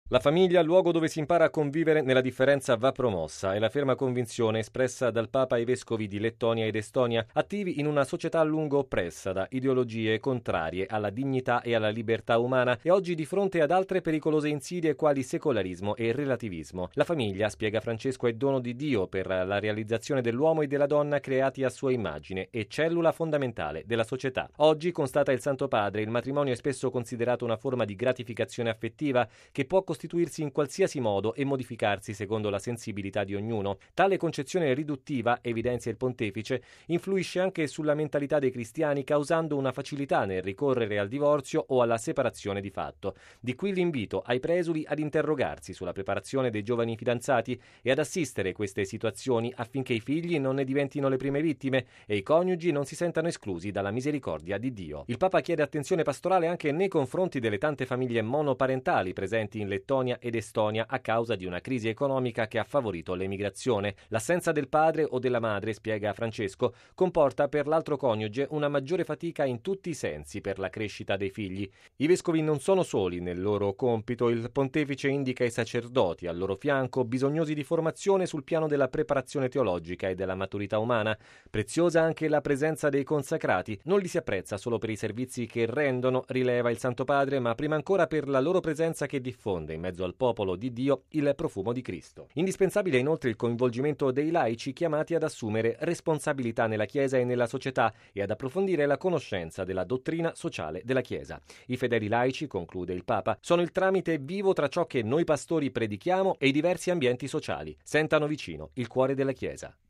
Bollettino Radiogiornale del 11/06/2015